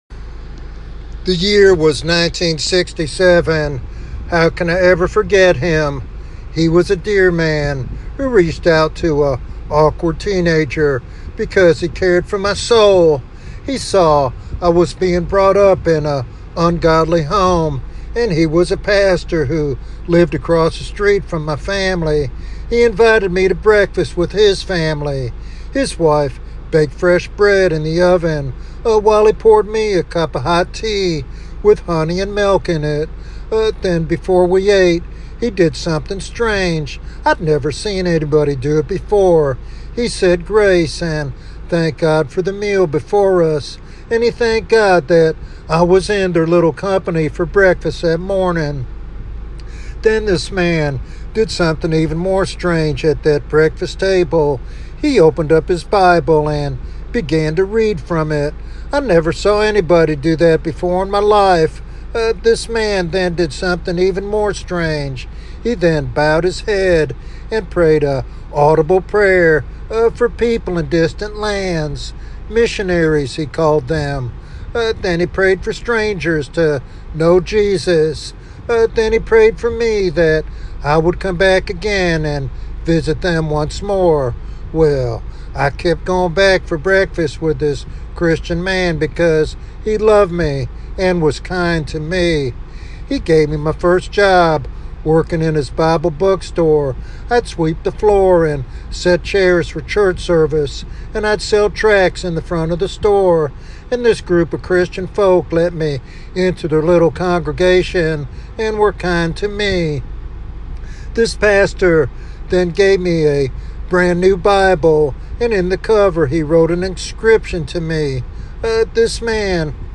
This sermon inspires a deeper commitment to evangelism and spiritual care.
Sermon Outline